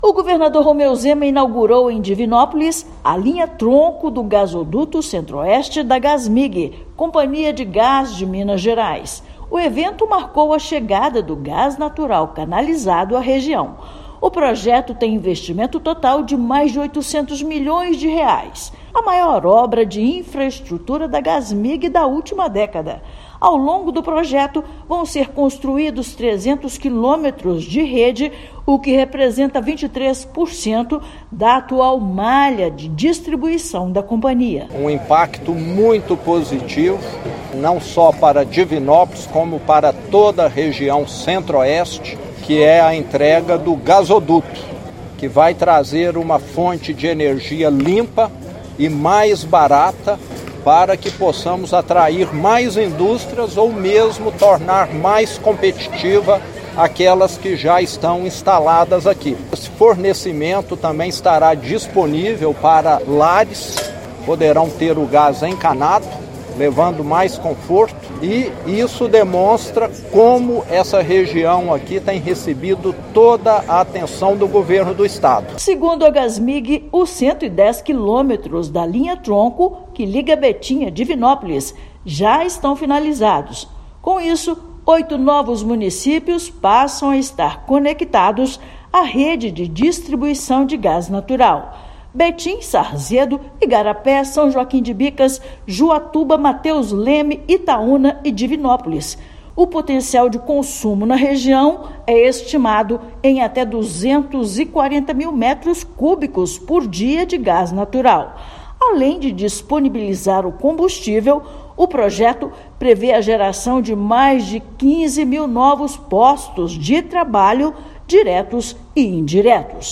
Oito novos municípios passam a estar diretamente conectados à rede de distribuição de gás natural com a inauguração da obra. Ouça matéria de rádio.